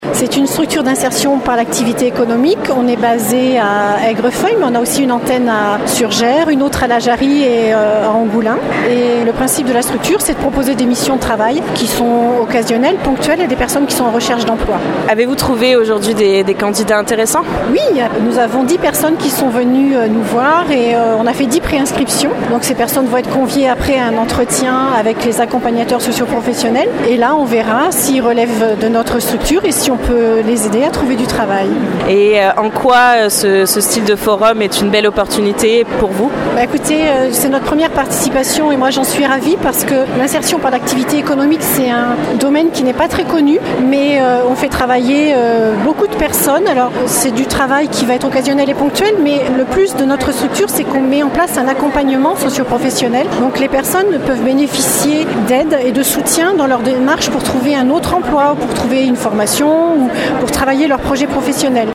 Forte affluence hier après-midi au forum Destination emploi.